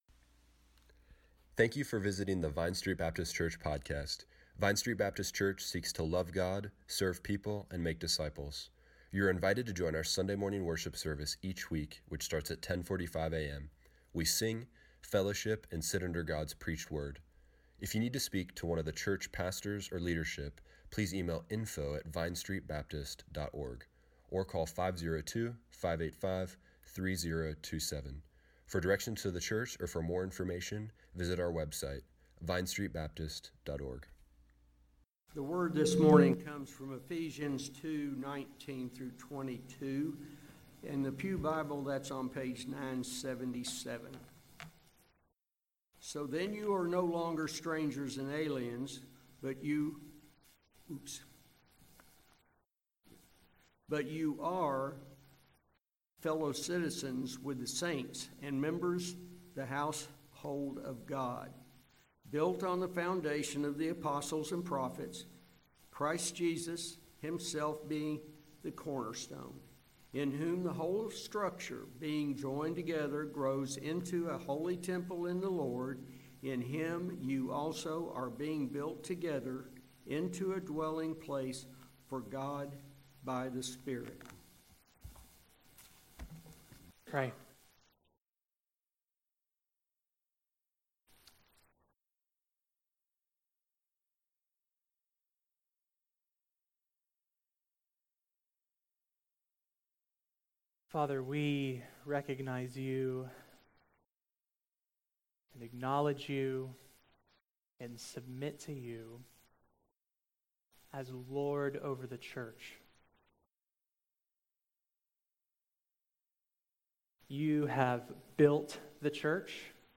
August 20, 2017 Morning Worship | Vine Street Baptist Church